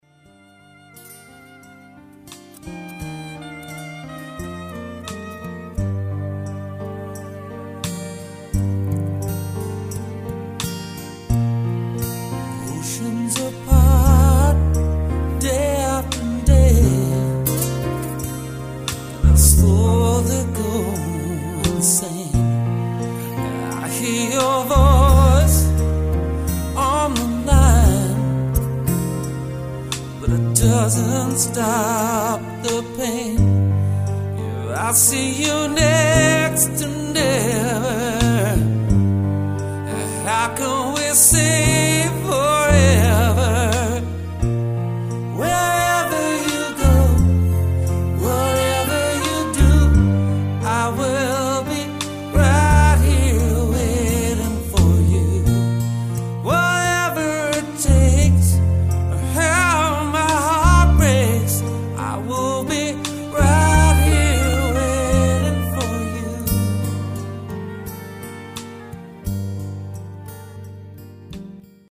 Guitar Vocal Entertainer
A Gifted Guitar Vocalist